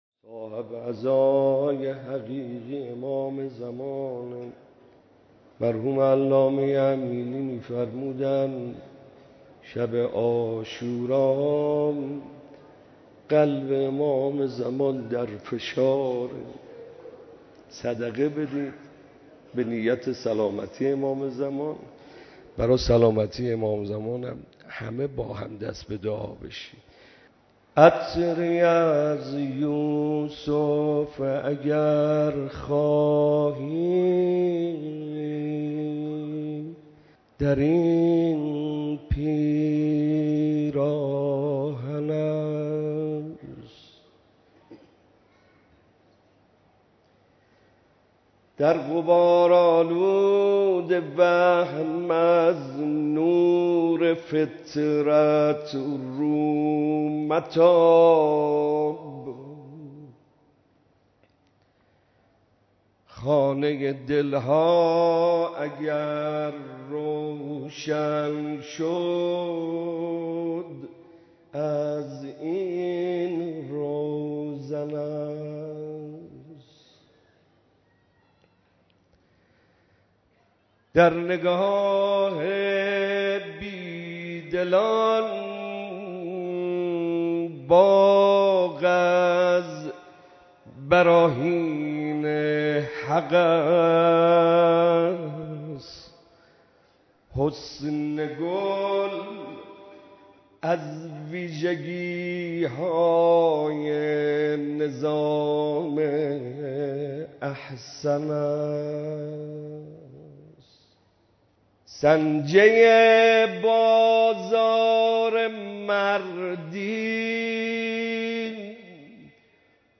مراسم عزاداری حضرت اباعبدالله الحسین علیه‌السلام در شب عاشورا
مراسم عزاداری شب عاشورای حسینی علیه‌السلام برگزار شد
مداحی